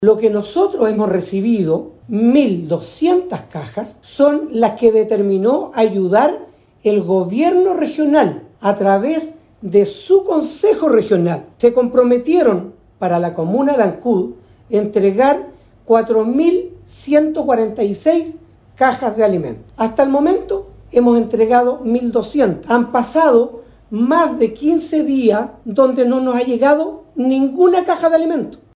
Sobre la manifestación de estos pobladores, aquejados por la nula ayuda en materia de alimentos básicos durante la emergencia, respondió el alcalde Carlos Gómez.